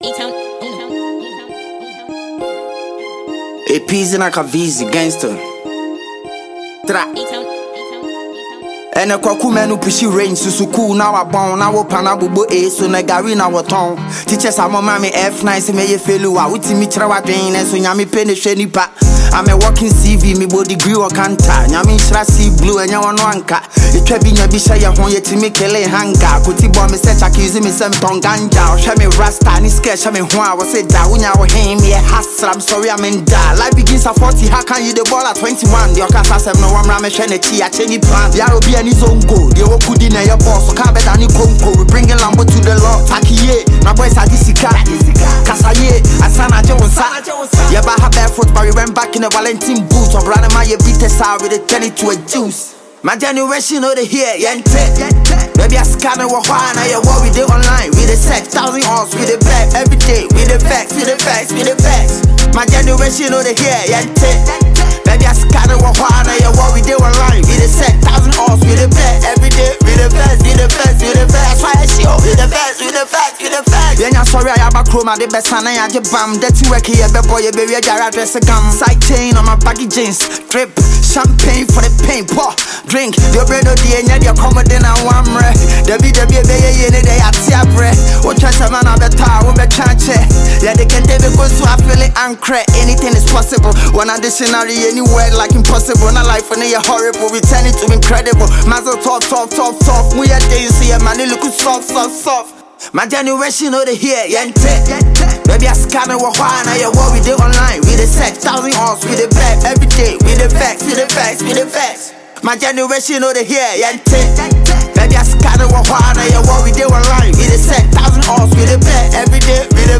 hardcore rap song